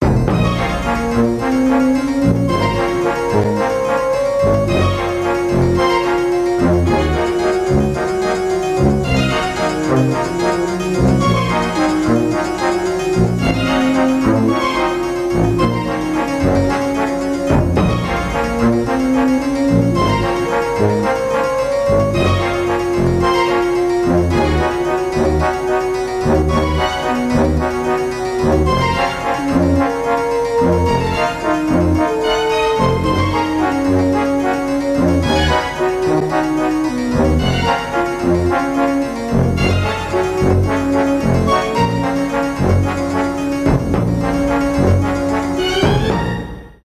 инструментальные
без слов